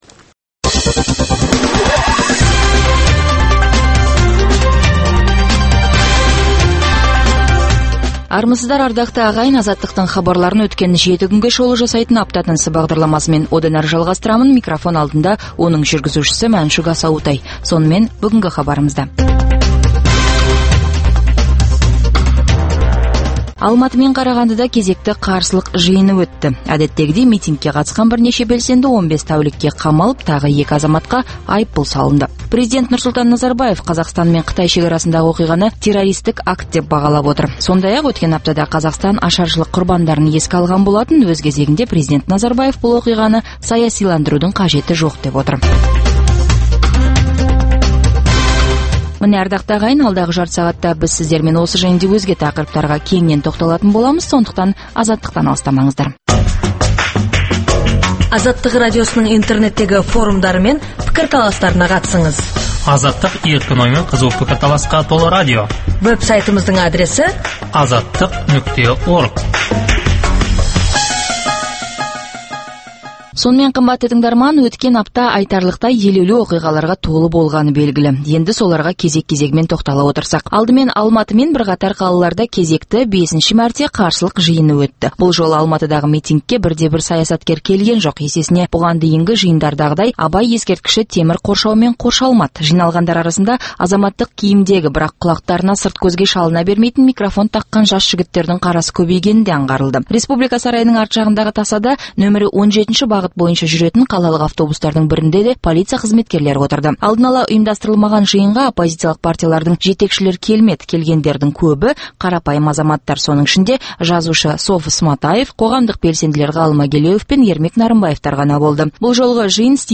Апта тынысы – Апта бойына орын алған маңызды оқиға, жаңалықтарға құрылған апталық шолу хабары.